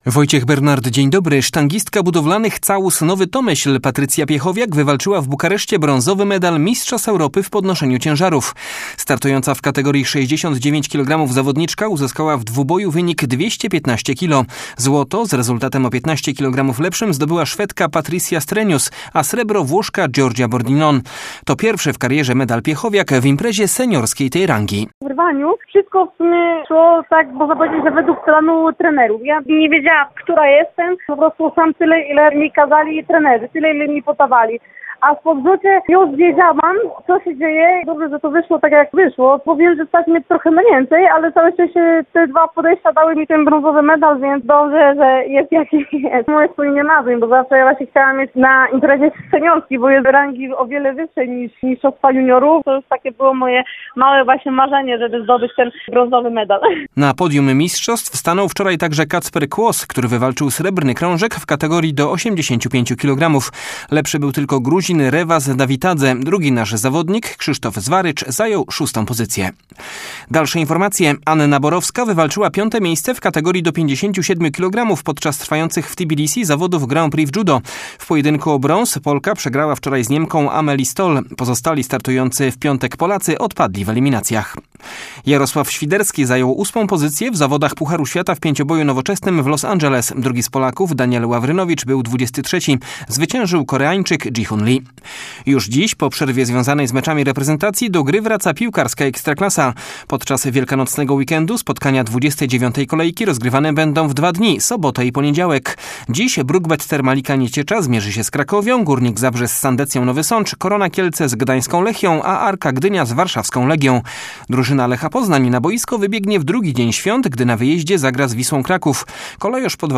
31.03 serwis sportowy godz. 7:05